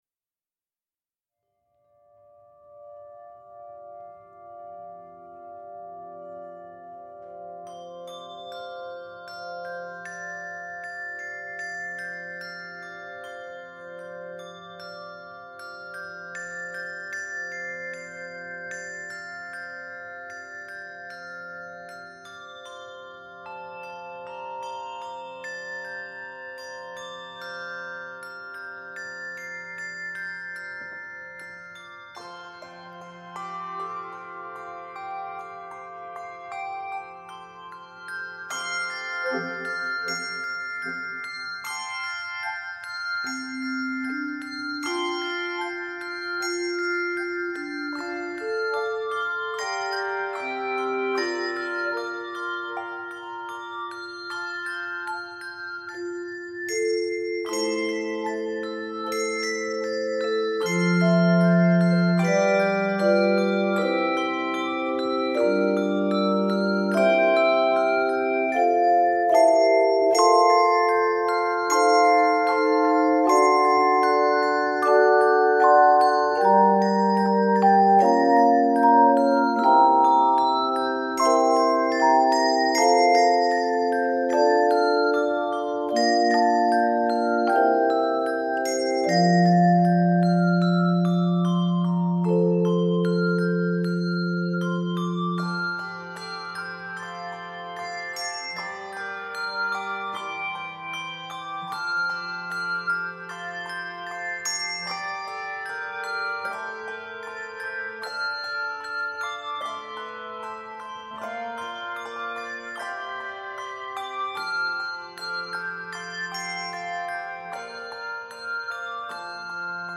original, lilting melody
Keys of F Major and Db Major.